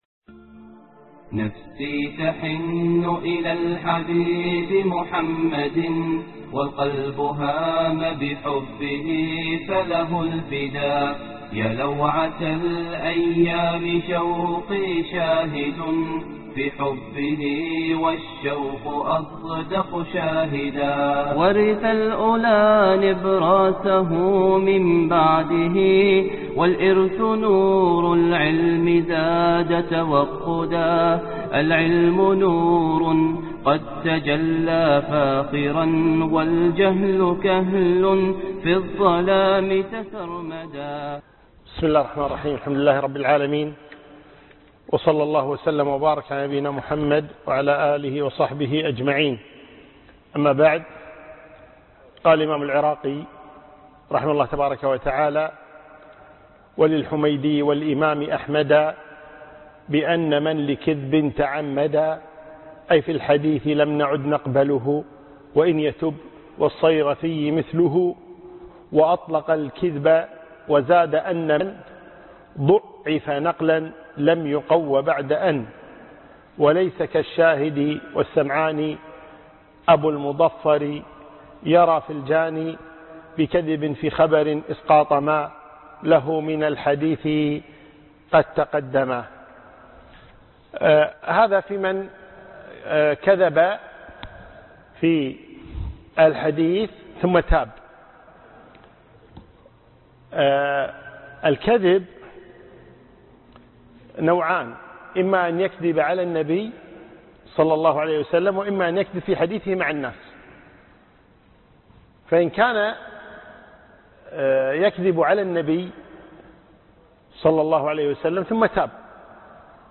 المحاضرة الرابعة _ ألفية العراقي - الشيخ عثمان الخميس